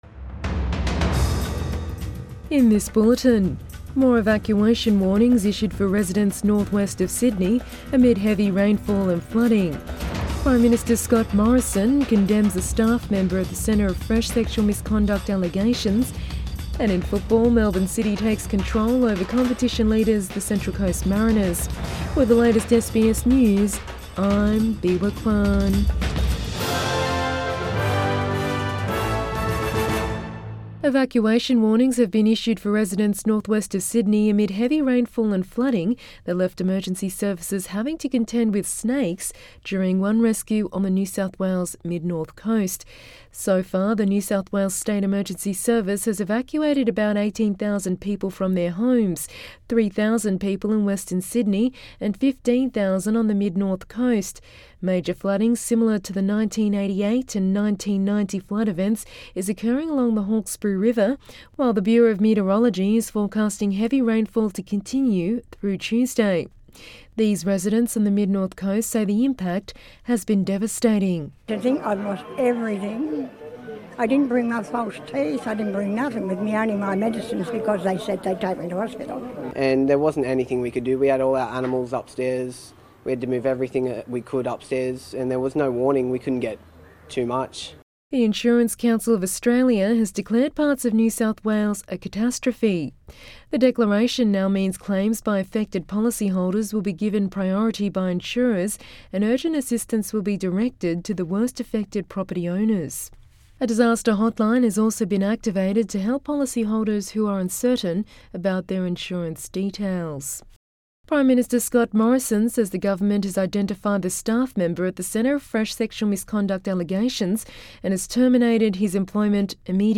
AM bulletin 23 February 2021